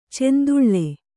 ♪ cenduḷḷe